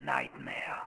1 channel
Whisp03C.wav